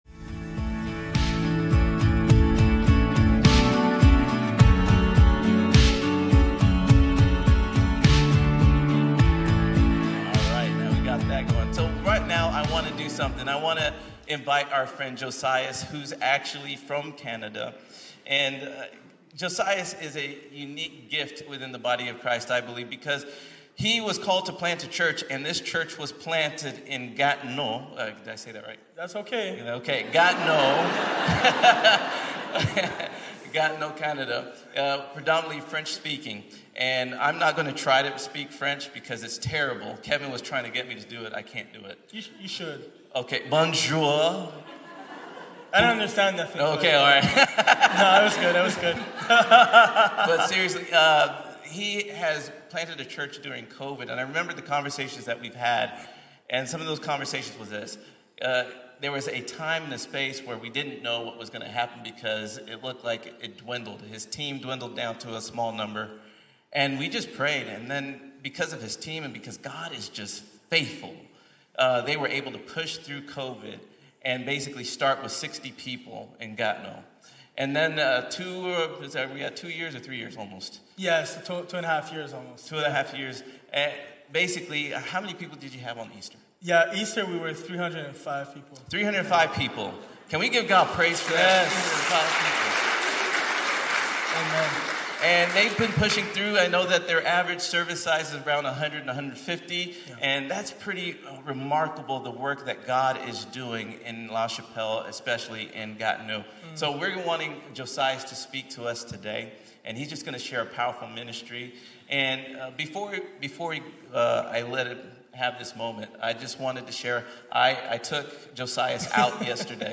Mission Sunday April 7 2024.mp3